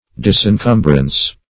Search Result for " disencumbrance" : The Collaborative International Dictionary of English v.0.48: Disencumbrance \Dis`en*cum"brance\, n. Freedom or deliverance from encumbrance, or anything burdensome or troublesome.
disencumbrance.mp3